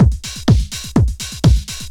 OSH Event Beat 1_125.wav